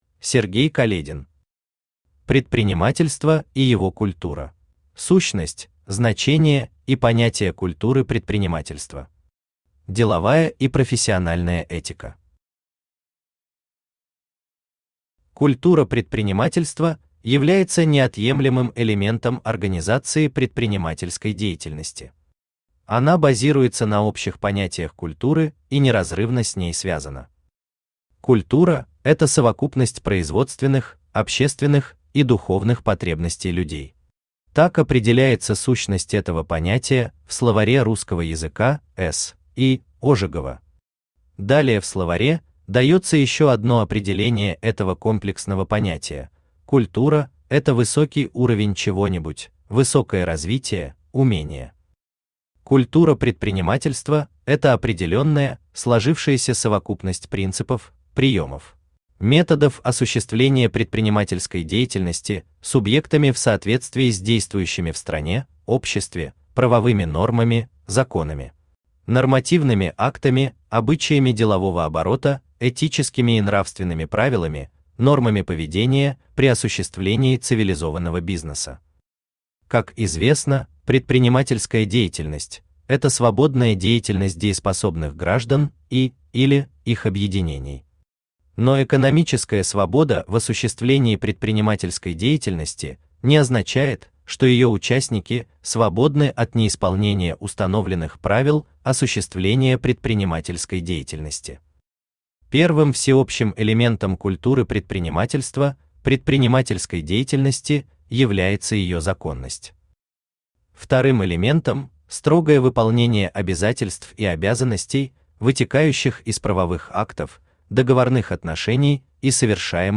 Аудиокнига Предпринимательство и его культура | Библиотека аудиокниг
Aудиокнига Предпринимательство и его культура Автор Сергей Каледин Читает аудиокнигу Авточтец ЛитРес.